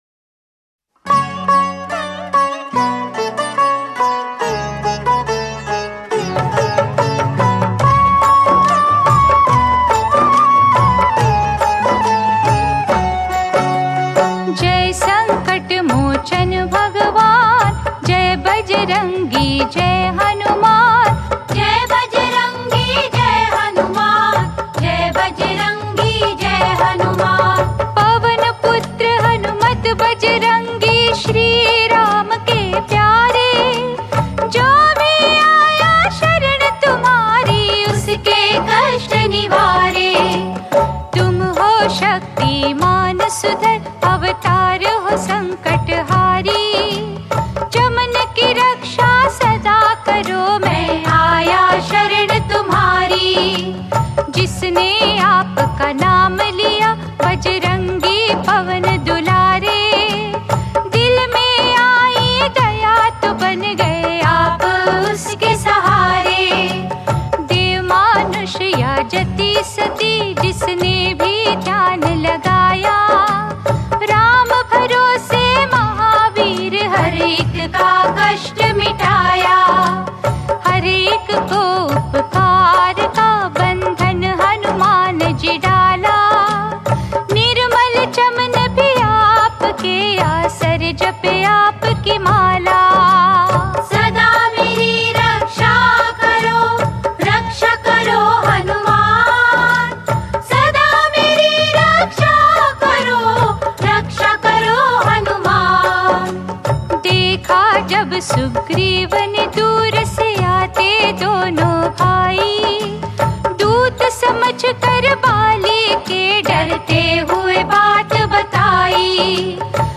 Shree Hanuman Bhajans